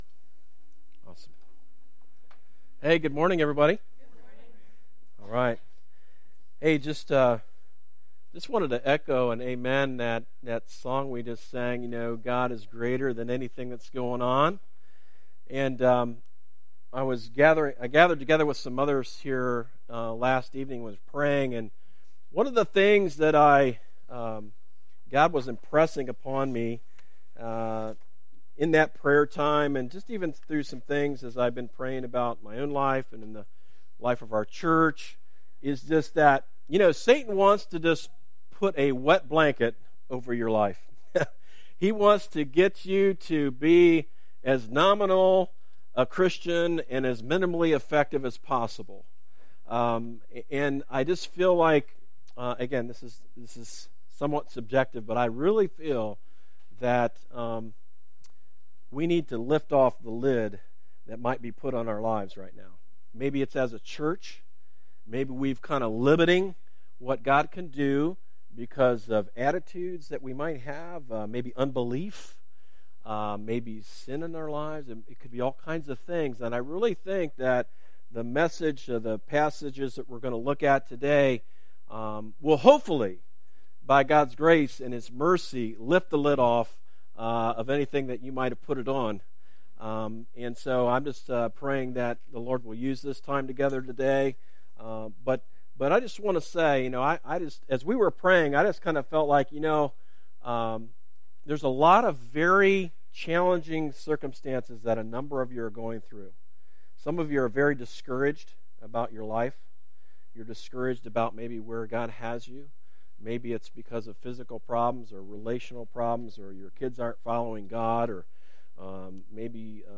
A message from the series "Stand Alone Sermons." In Psalm 121 God gives us a glimpse of His providential care for His people.